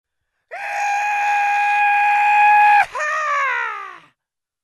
Здесь вы найдете громкие призывы воинов, ритуальные возгласы и современные мотивационные крики.
Боевой клич - Версия 2